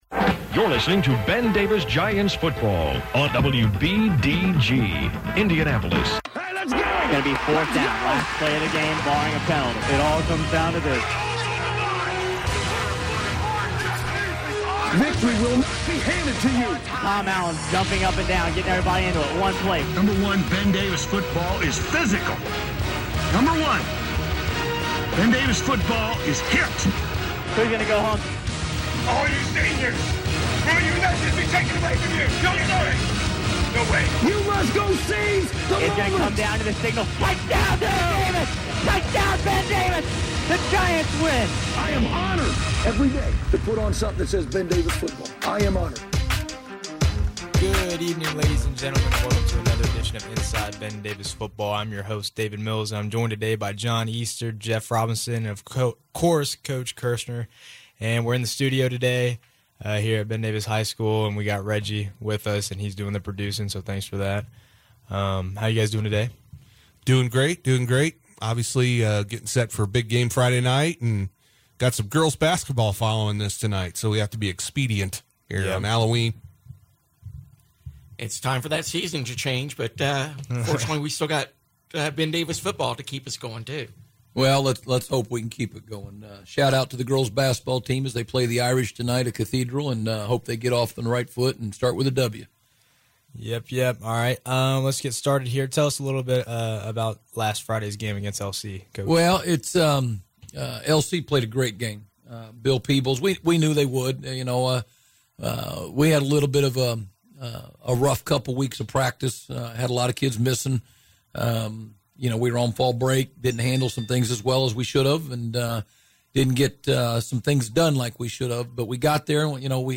Recorded live on 10/31/17.